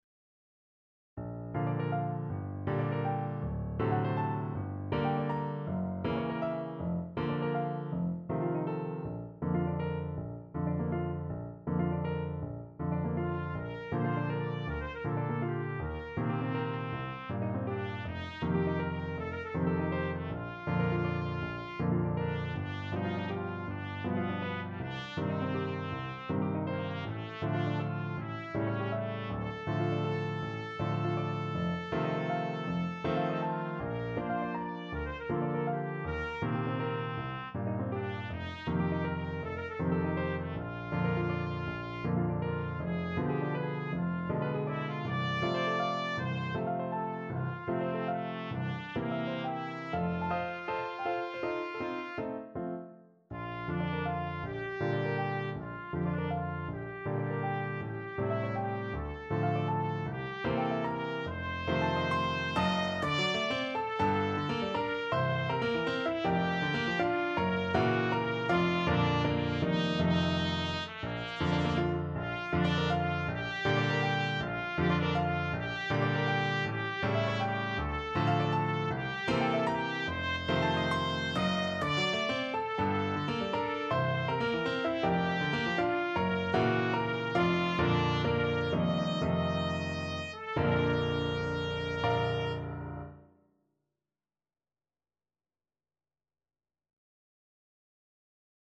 Trumpet version
6/8 (View more 6/8 Music)
G4-Eb6
Classical (View more Classical Trumpet Music)